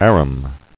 [ar·um]